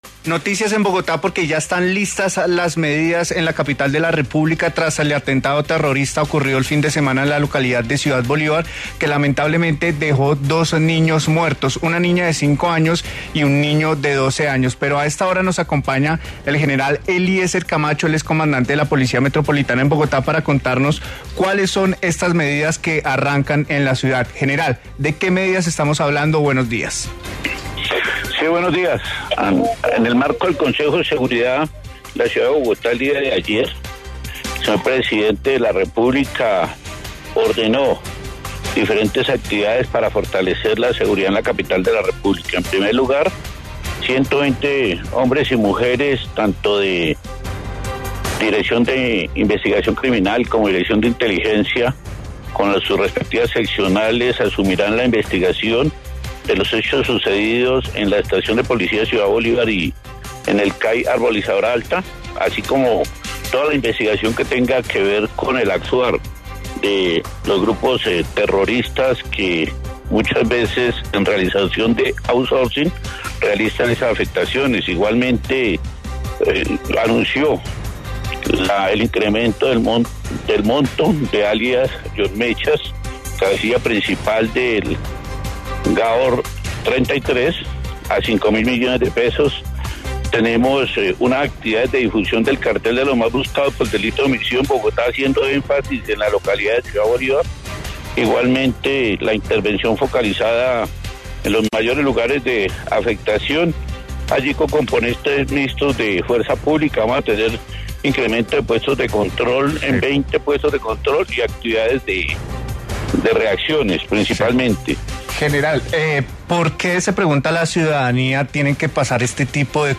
En diálogo con La W, el general Eliécer Camacho hizo un balance sobre las medidas adoptadas tras el consejo de seguridad de emergencia llevado a cabo en Bogotá tras el atentado del pasado 26 de marzo.